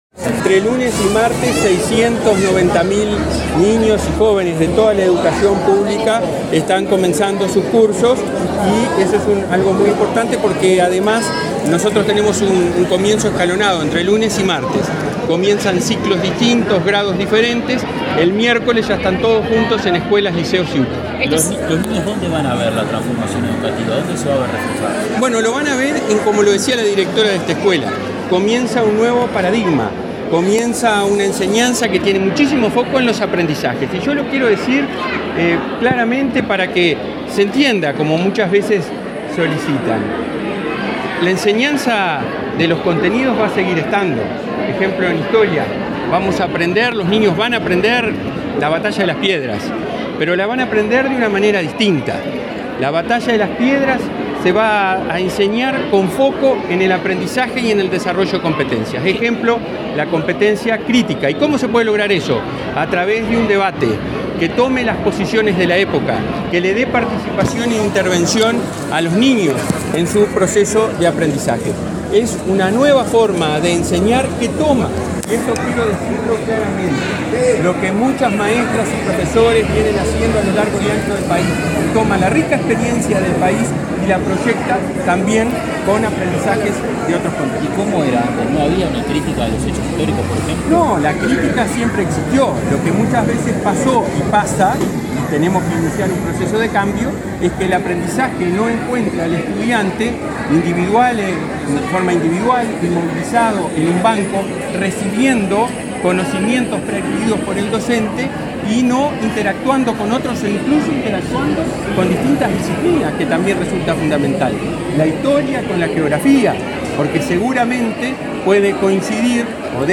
Declaraciones del presidente de la ANEP, Robert Silva
Declaraciones del presidente de la ANEP, Robert Silva 06/03/2023 Compartir Facebook X Copiar enlace WhatsApp LinkedIn El presidente de la ANEP, Robert Silva, recorrió varios centros educativos este lunes 6, por el inicio del año lectivo, y, en ese contexto, dialogó con la prensa.